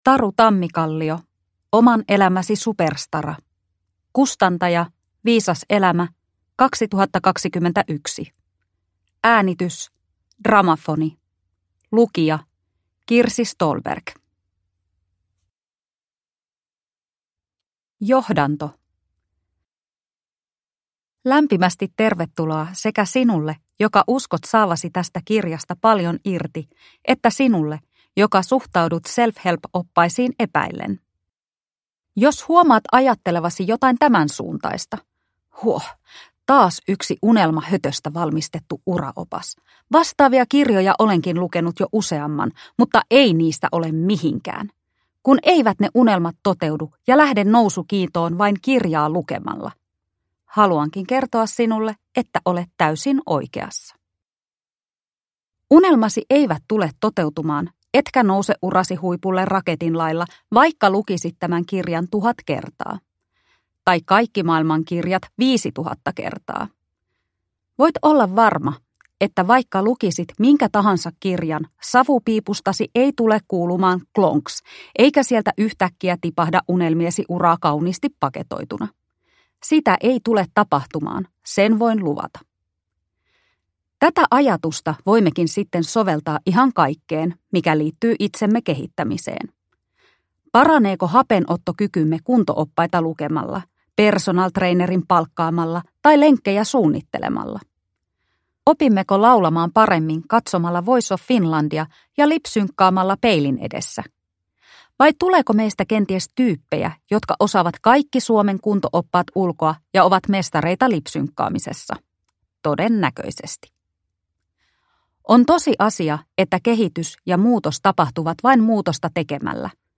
Oman elämäsi superstara – Ljudbok – Laddas ner